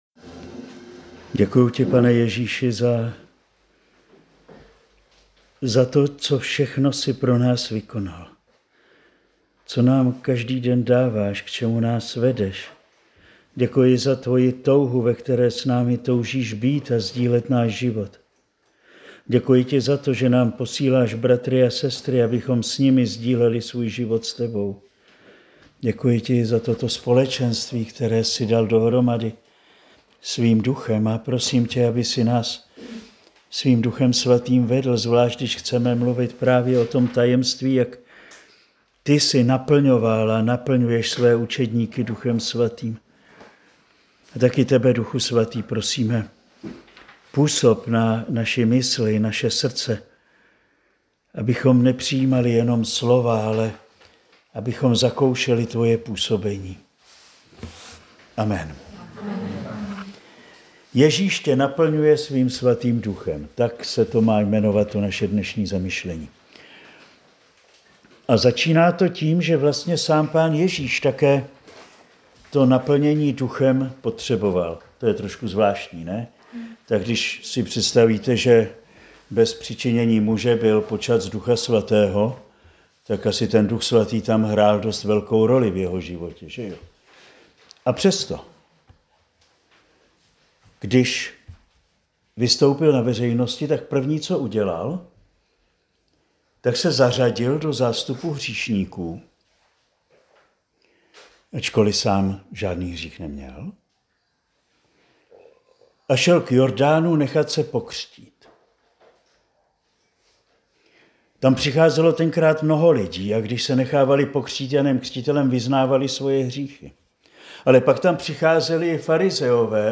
Přednáška zazněla na semináři Život v Duchu dne 4. 5. 2025